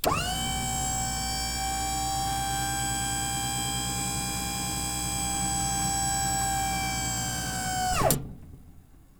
hydraulic_complete.wav